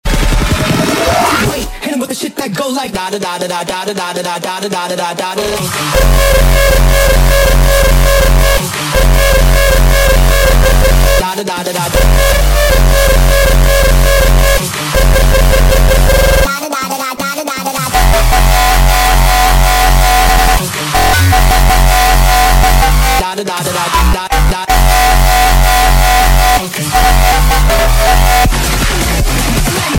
Rawtempo - Rawstyle - Uptempo - Zaagkicks